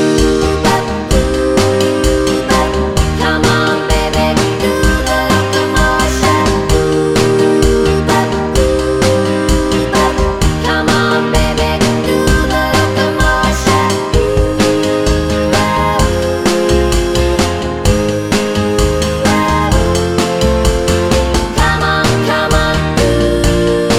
no sax solo Pop (1960s) 2:25 Buy £1.50